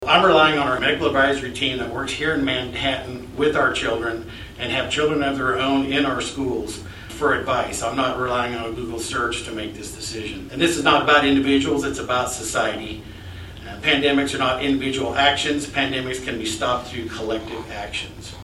The board held a special meeting Wednesday night to adopt the plan before the start of classes.